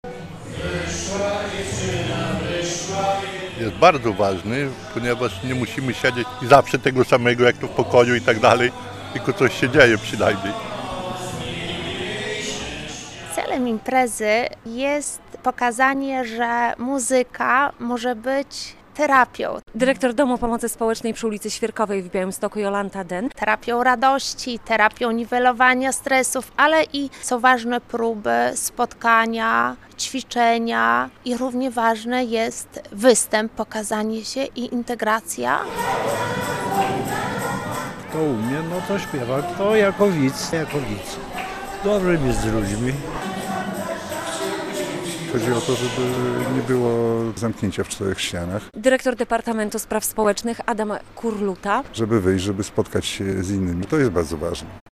Mieszkańcy 11 Domów Pomocy Społecznej spotkali się we wtorek (13.06) w Białymstoku na II Wojewódzkim Przeglądzie Piosenki Ludowej "Na Ludową Nutę".